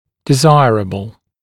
[dɪ’zaɪərəbl][ди’зайэрэбл]желательный